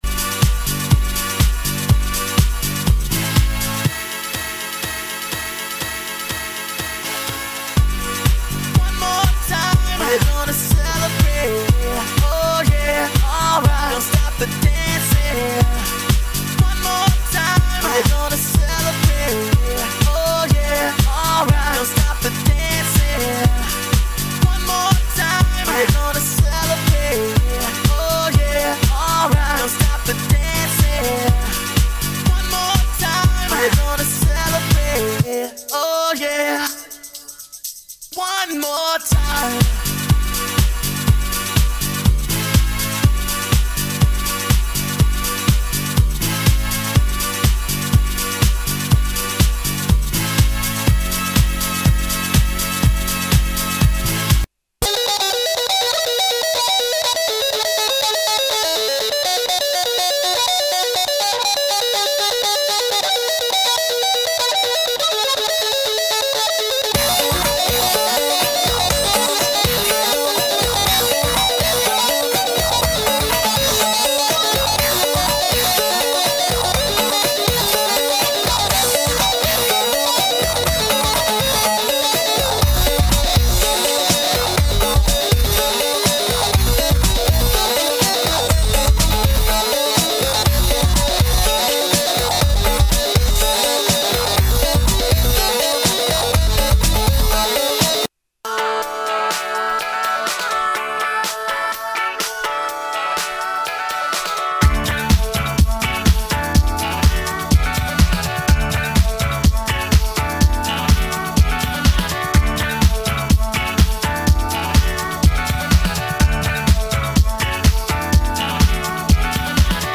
House-Trance